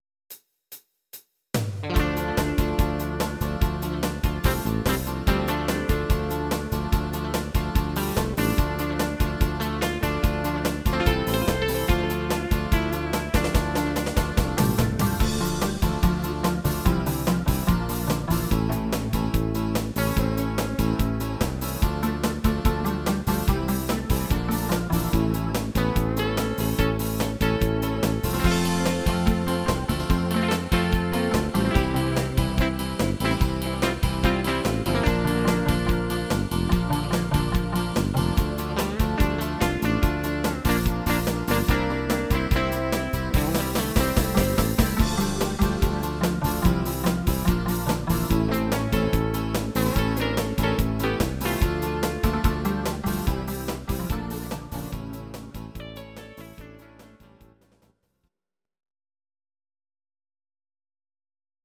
These are MP3 versions of our MIDI file catalogue.
Your-Mix: Rock (2970)